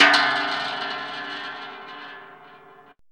METAL HIT 5.wav